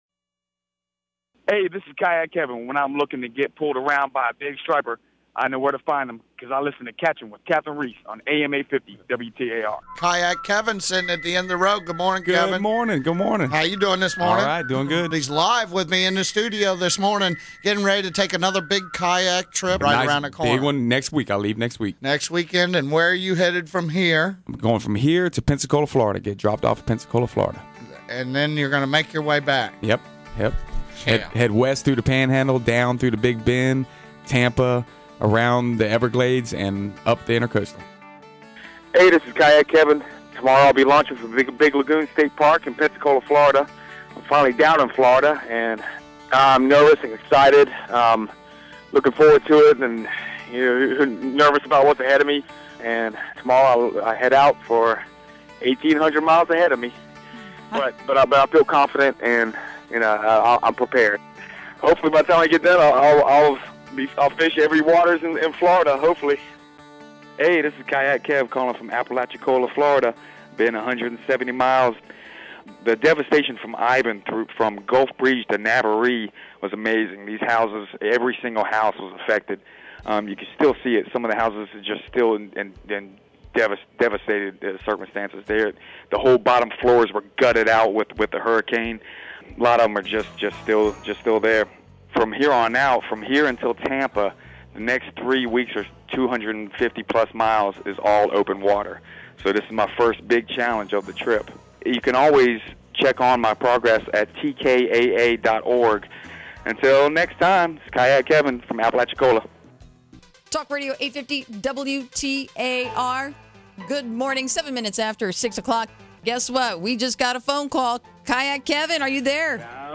audio documentary